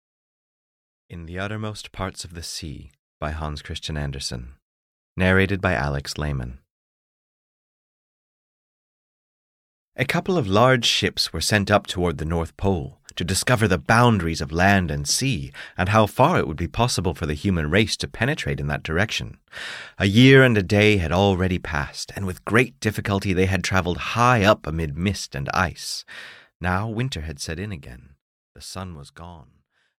In the Uttermost Parts of the Sea (EN) audiokniha
Ukázka z knihy